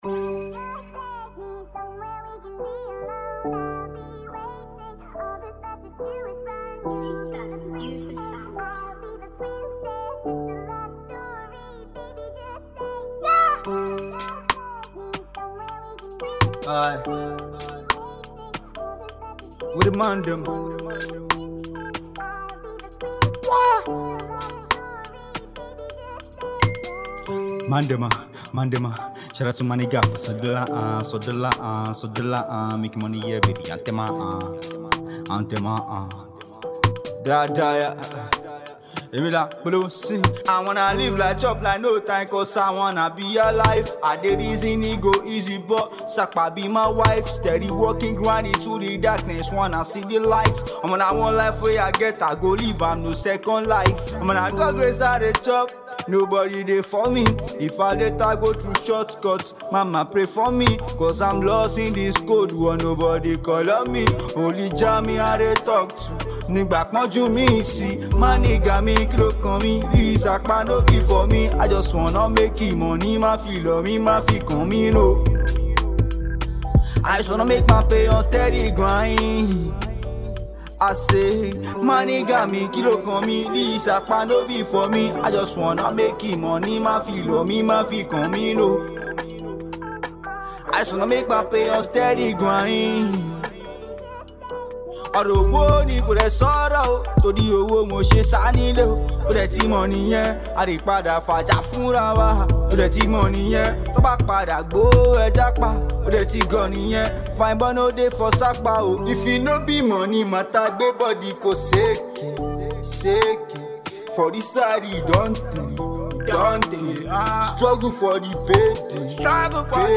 simple, infectious, and built to get people moving.